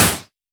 Index of /musicradar/retro-drum-machine-samples/Drums Hits/WEM Copicat
RDM_Copicat_SY1-Snr02.wav